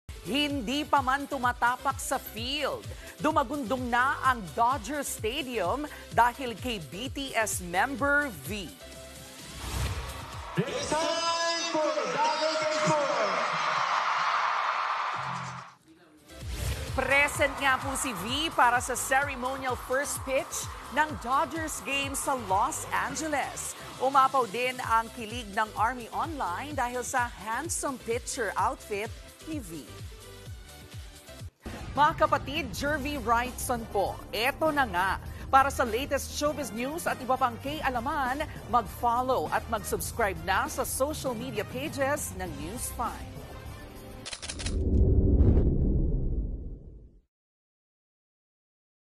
Frontline Philippines News talking sound effects free download